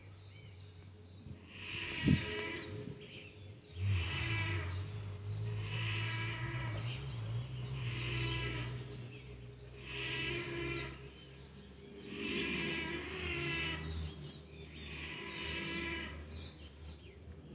Bandurria Austral (Theristicus melanopis)
Pollo-Bandurria-Casa-2026-01-13.mp3
Nombre en inglés: Black-faced Ibis
Fase de la vida: Varios
Provincia / Departamento: Río Negro
Condición: Silvestre
Certeza: Fotografiada, Vocalización Grabada